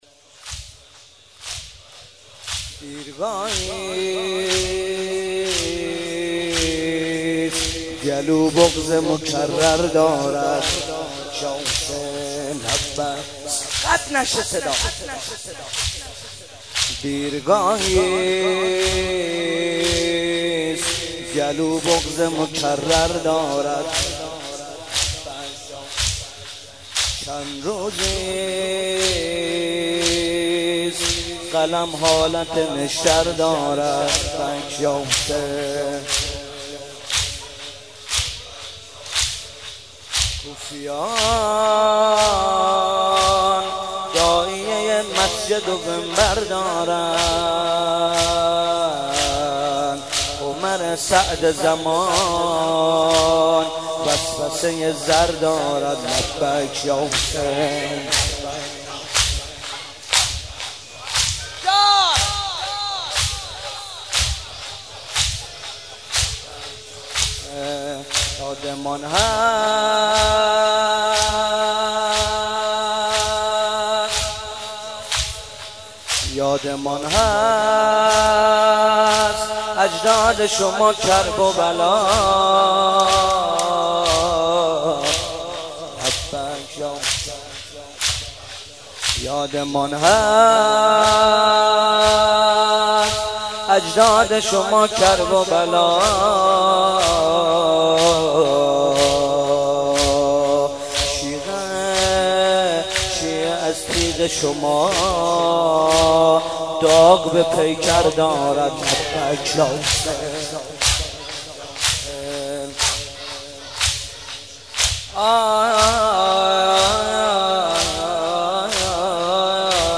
03.sineh zani1.mp3